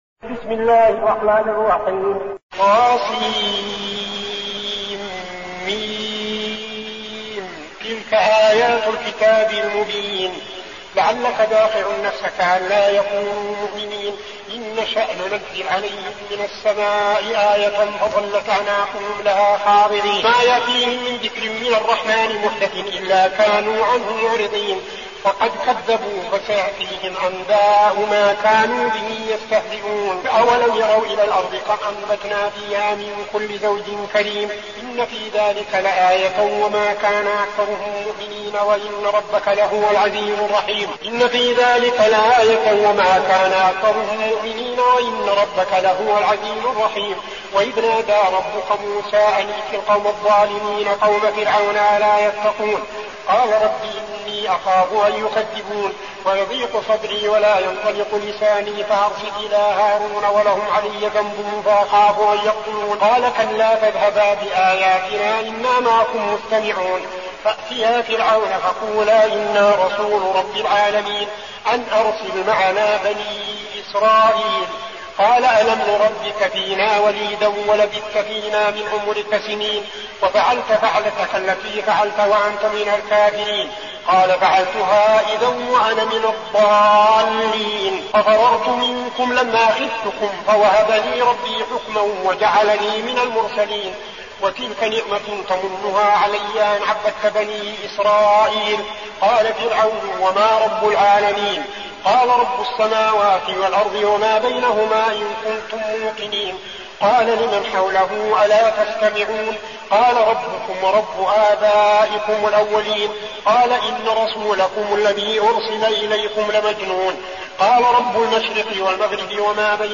المكان: المسجد النبوي الشيخ: فضيلة الشيخ عبدالعزيز بن صالح فضيلة الشيخ عبدالعزيز بن صالح الشعراء